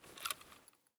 EFT Aim Rattle